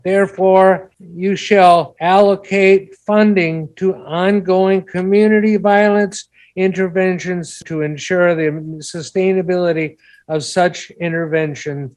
One of them read from the county’s own resolution passed last September, which set a clear course.